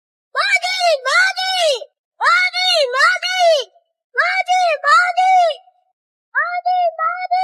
MODI MODI, MODI MODI (kids) - Bouton d'effet sonore